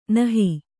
♪ nahi